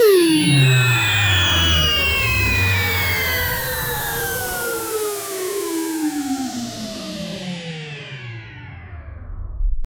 Alien spacecraft landing and powering down
alien-spacecraft-landing-and-powering-down-imgrncxs.wav